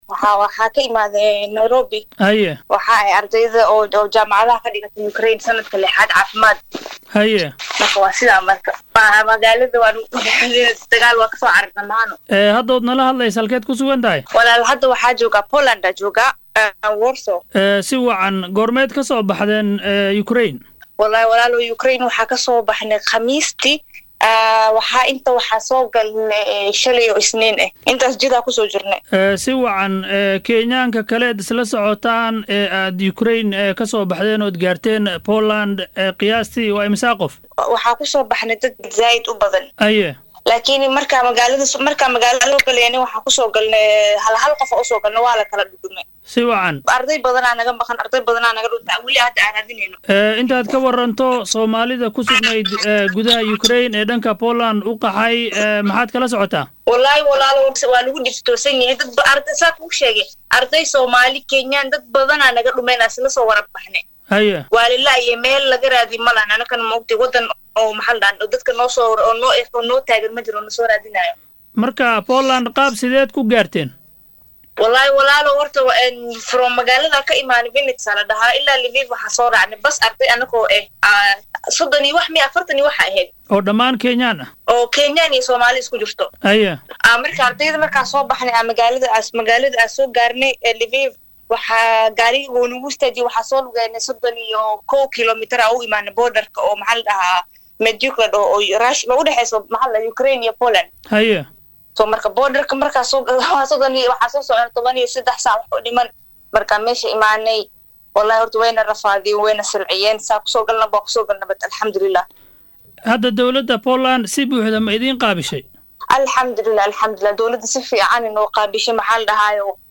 DHAGEYSO:Wareysi gaar ah: Ardayad ka qaxday Ukraine oo 13 saacadood lugeynaysay